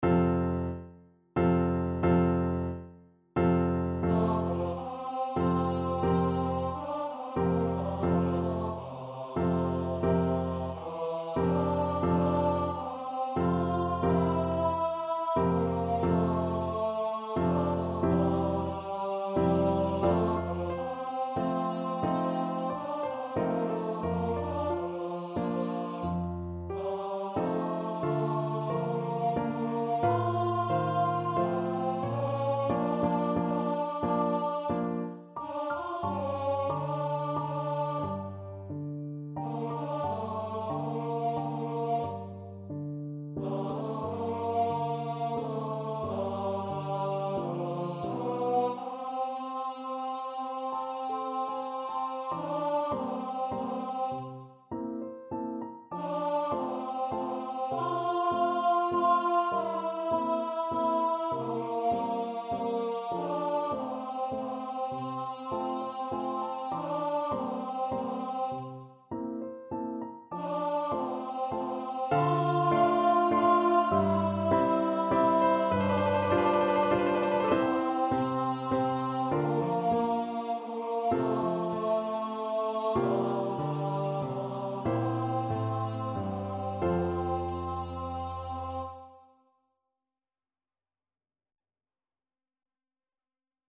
Tenor Voice
F major (Sounding Pitch) (View more F major Music for Tenor Voice )
~ = 90 Allegretto moderato
3/4 (View more 3/4 Music)
Classical (View more Classical Tenor Voice Music)